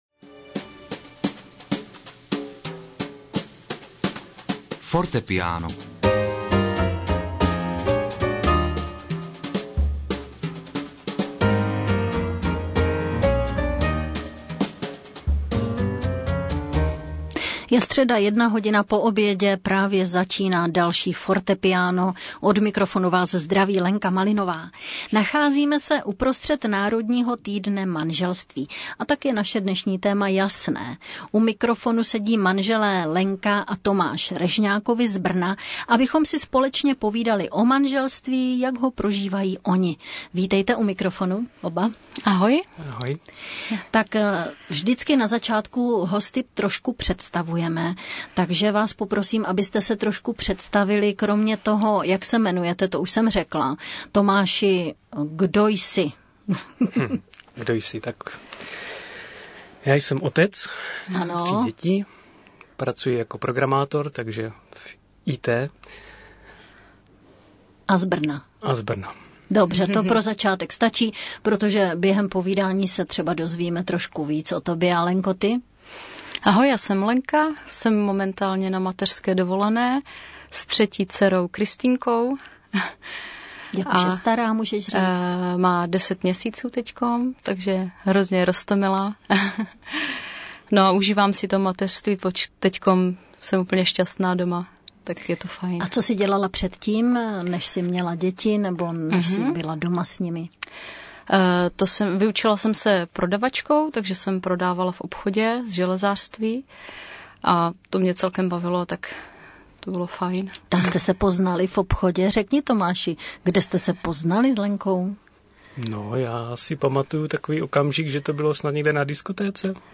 Pořad se vysílal živě ve středu 12. února od 13:00 a byl reprízován v sobotu 15. 2. od desáté hodiny dopolední.